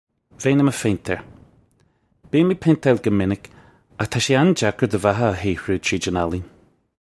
Pronunciation for how to say
Vay-in immuh fayn-tare. Bee-im ig paint-ile guh minic okh taw shay an-jacker duh vaha uh hee-hroo chreej on ah-leen
This is an approximate phonetic pronunciation of the phrase.